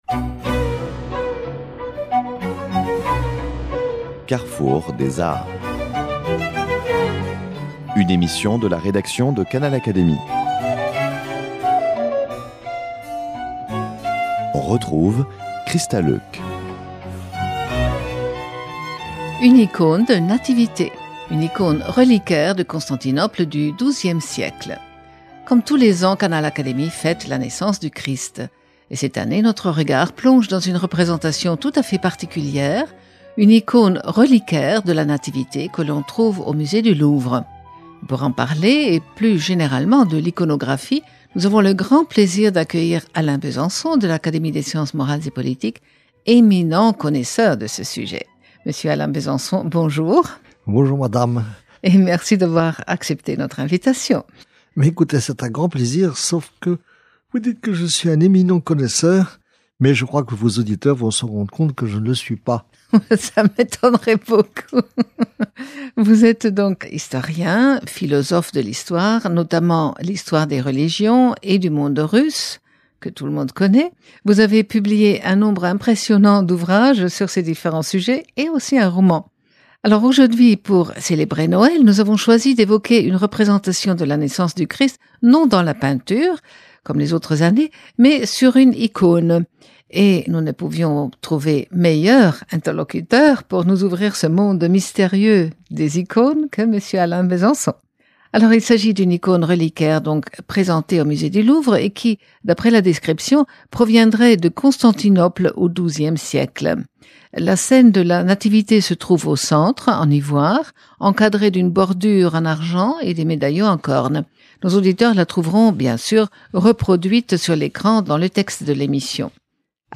Une icône de nativité, présentée par Alain Besançon, de l’Académie des sciences morales et politiques